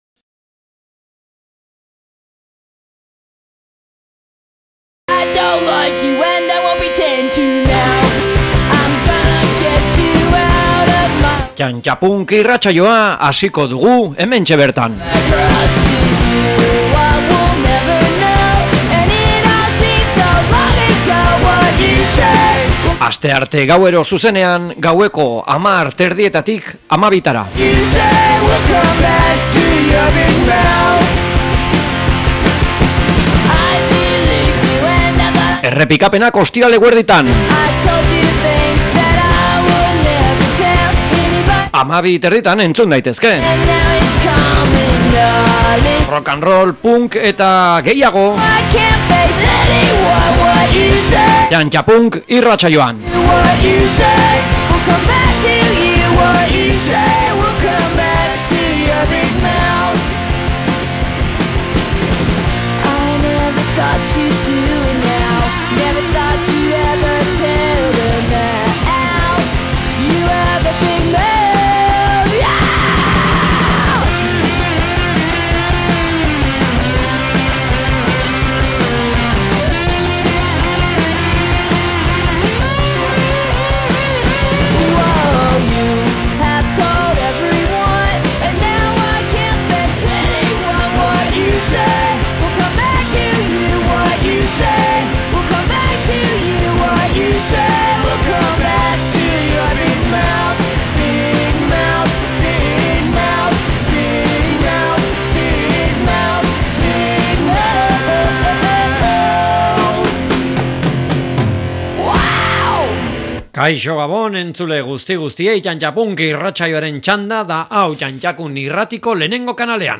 TtanTtaPunk programa rock’n'roll-aren inguruko programa da. Bariante diferenteak jorratu eta entzuteko aukera duzu, 60, 70 eta 80. hamarkadari dagokionez, eta nagusiki Punk estiloari gehien hurbiltzen zaiona.